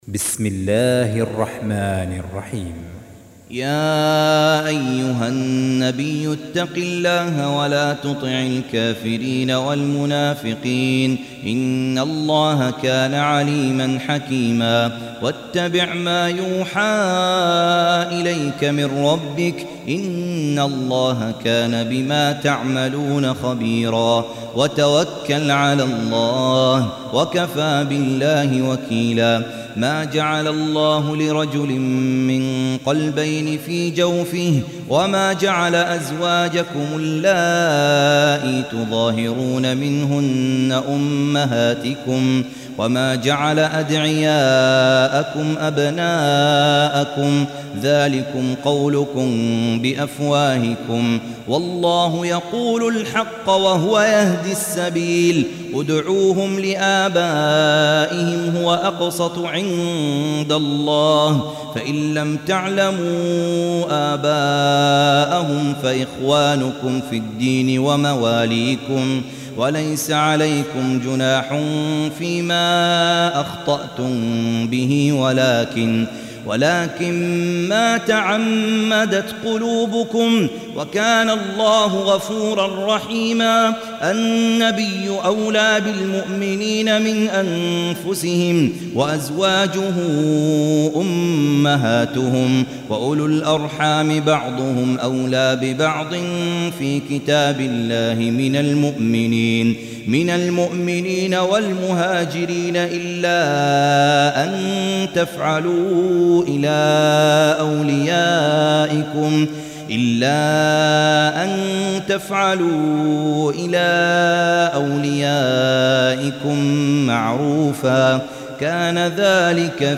Surah Repeating تكرار السورة Download Surah حمّل السورة Reciting Murattalah Audio for 33. Surah Al�Ahz�b سورة الأحزاب N.B *Surah Includes Al-Basmalah Reciters Sequents تتابع التلاوات Reciters Repeats تكرار التلاوات